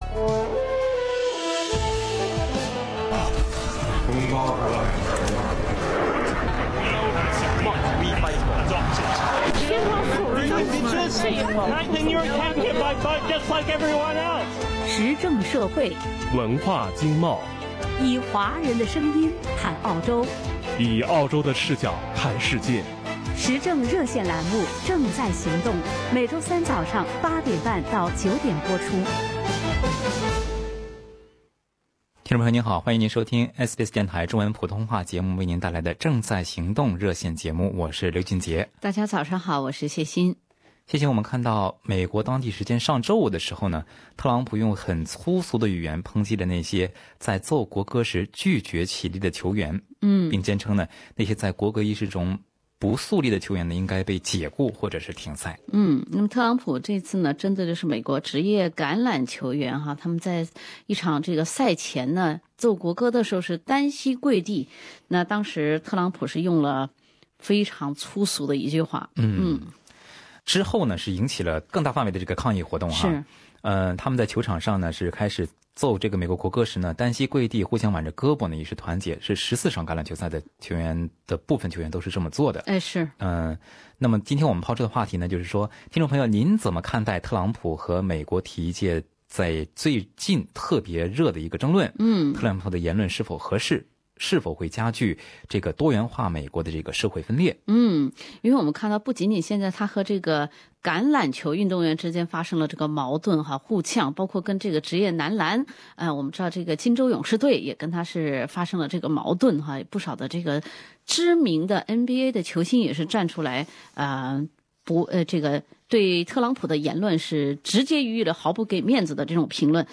欢迎您点击收听本期《正在行动》节目，了解热线听众表达的观点。